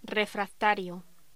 Locución: Refractario
voz
Sonidos: Voz humana